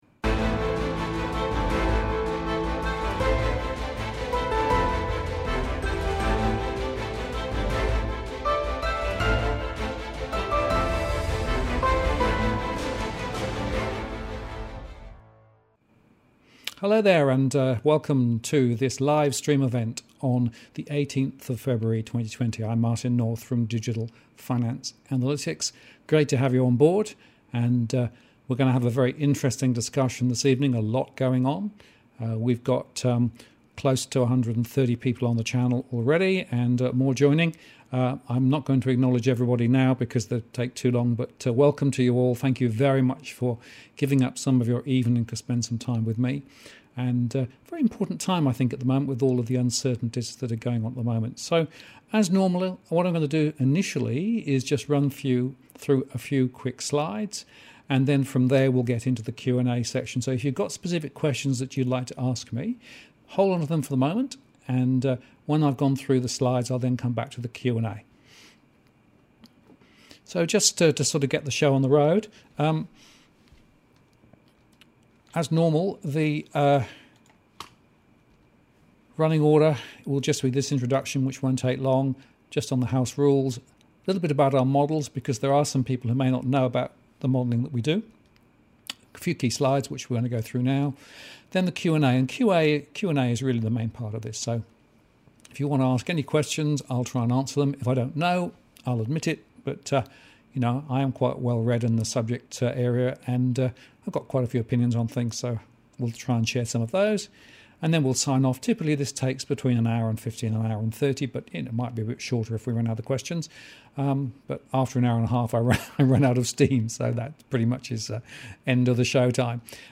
Updated Finance And Property Scenarios (Recorded Live Stream)
A recording of our Live Stream Event discussion the latest finance and property news.